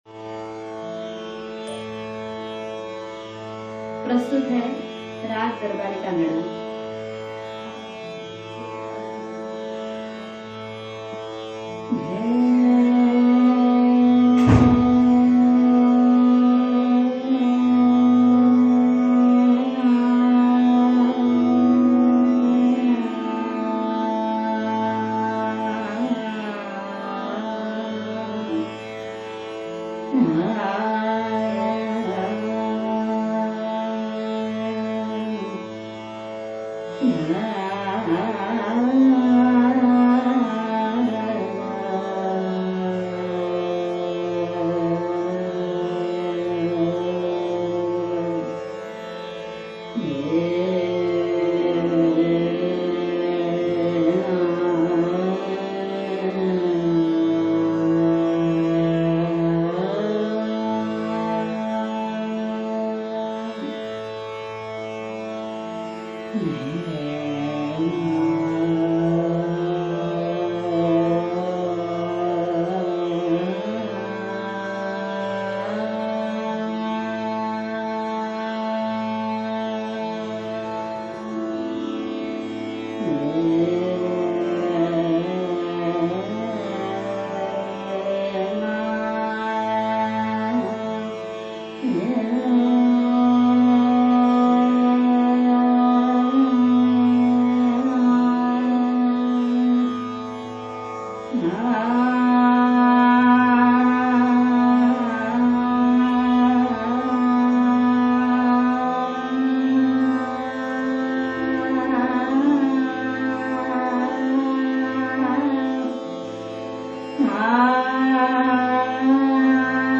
Asavari thaat · karuna rasa · graha: Saturn
Direct link: Darbari Kanada Darbari Kanada · full · instrumental Why now?
Slow, deep, stately sanchara cools late-night pitta and grounds vata — traditionally a royal-court meditation raga.
Slow, grave, and meditative; Kanada-family.